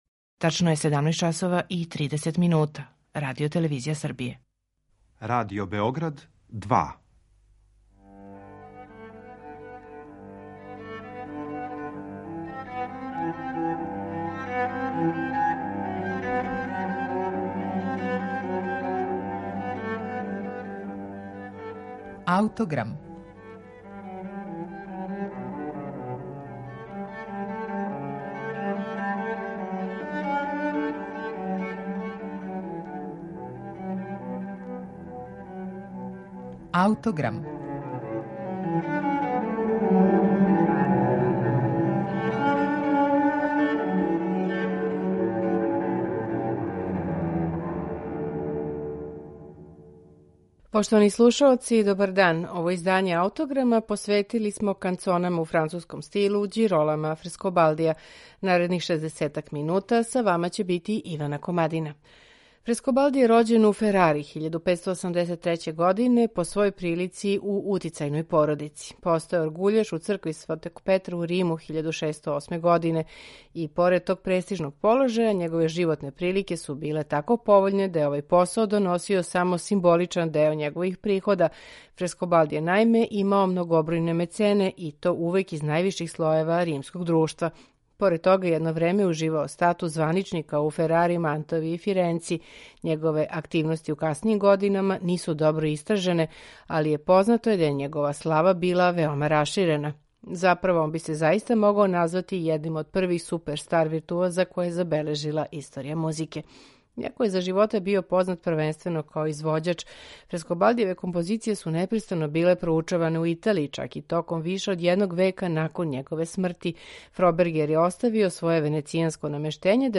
Фрескобалдијеве канцоне су писане за чембало или оргуље, а могле су да буду извођене у најразличитијим приликама, и духовним и световним.
оргуљаша и чембалисте